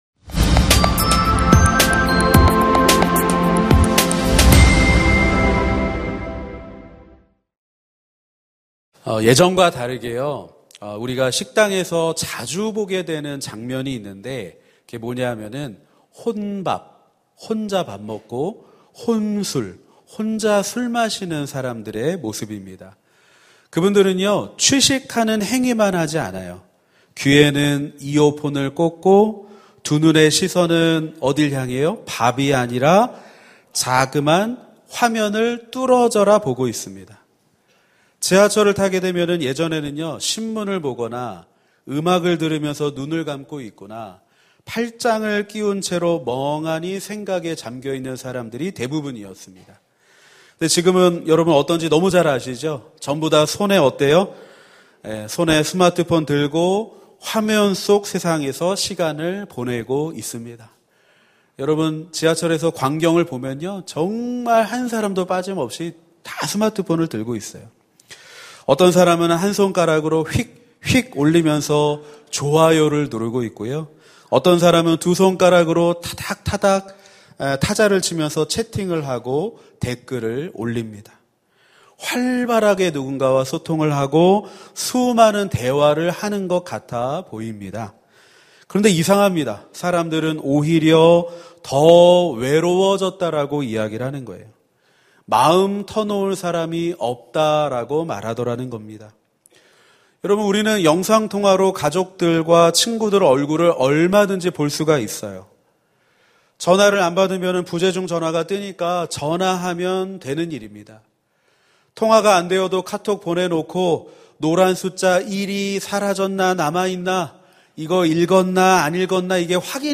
설교 : 시니어예배